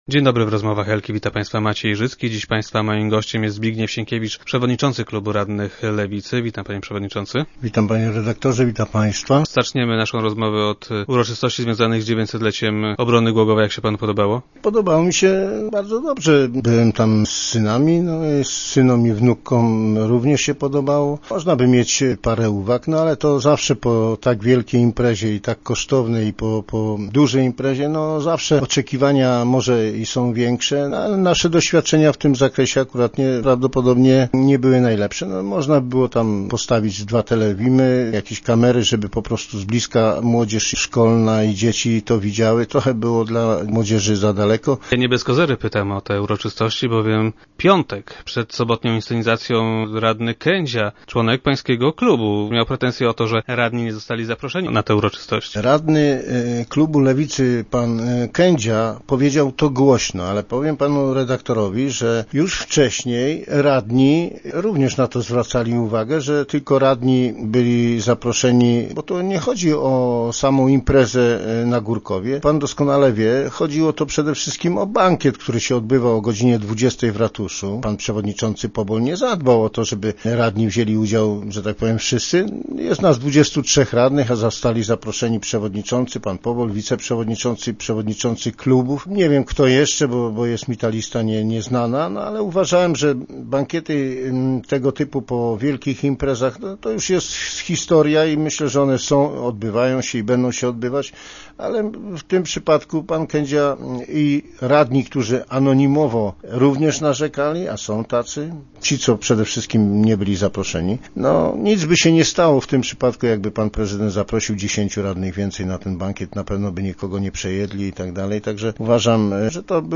0911_sienkiewicz_do_rozmw.jpgPodczas najbliższej sesji rady miasta  radni lewicy zamierzają złożyć wniosek o odwołanie Radosława Pobola z funkcji przewodniczącego rady. Jak powiedział Zbigniew Sienkiewicz, szef klubu lewicowych radnych oraz gość dzisiejszych Rozmów Elki, Pobol nie nadaję się na to stanowisko.